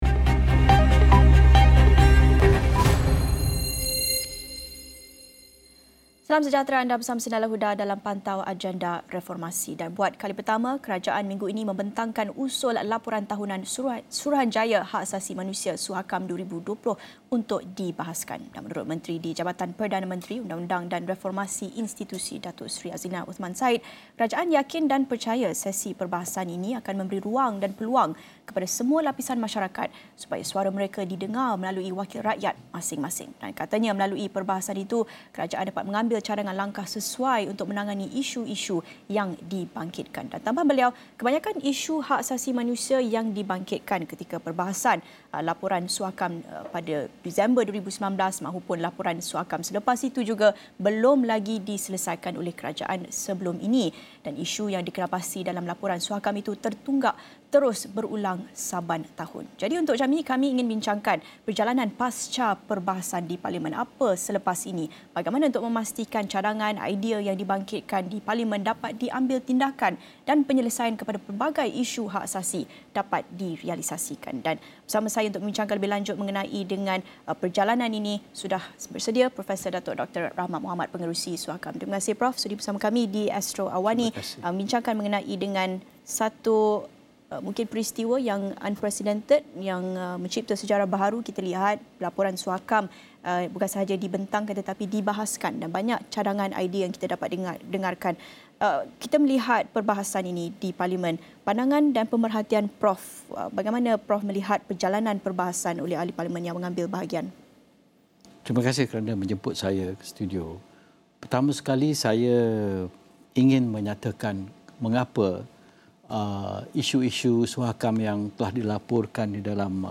Sejauh mana pandangan dan idea daripada Laporan Tahunan SUHAKAM 2020 yang dibahaskan baru – baru ini akan diambil kira untuk ditambah baik? Diskusi 5 petang